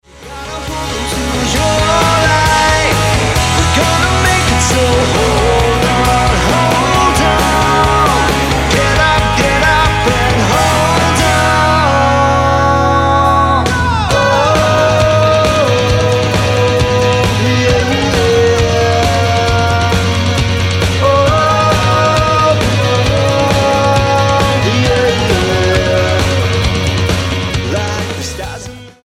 the four piece pop rock band
Rock Album